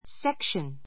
sékʃən